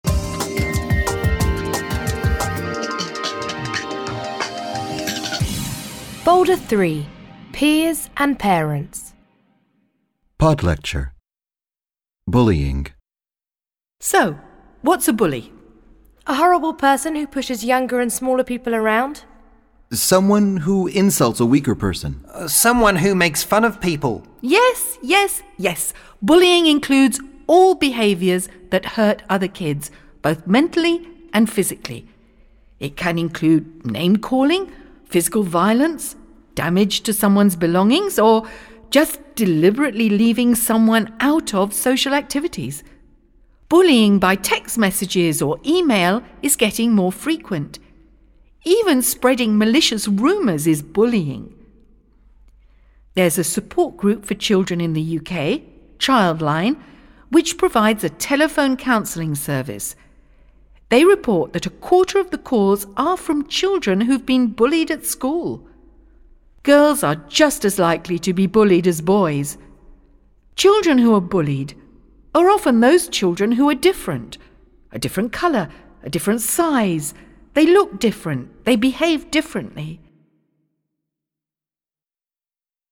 pod lecture : Bullying
01-pod-lecture-bullying.mp3